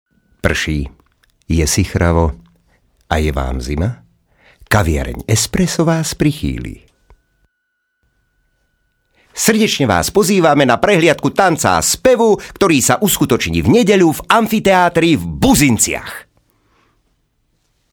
Professioneller slowakischer Sprecher für TV/Rundfunk/Industrie.
Kein Dialekt
Sprechprobe: Industrie (Muttersprache):
Professionell slovakian voice over artist